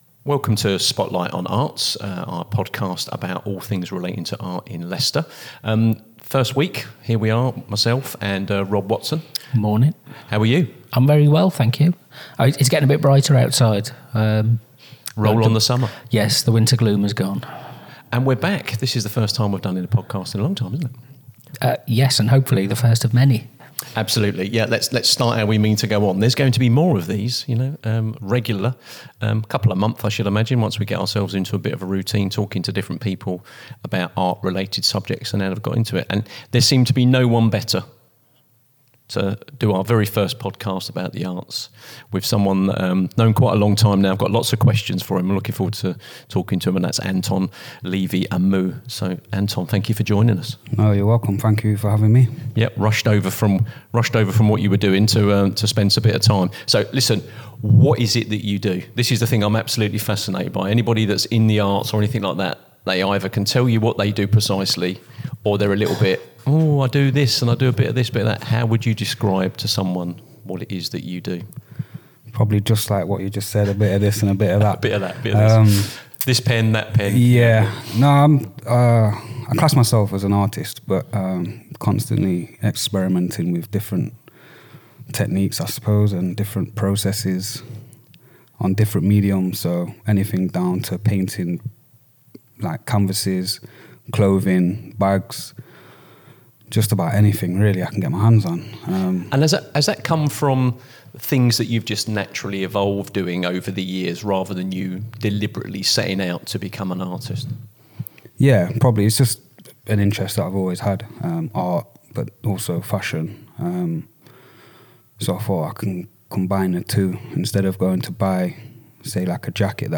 In this conversation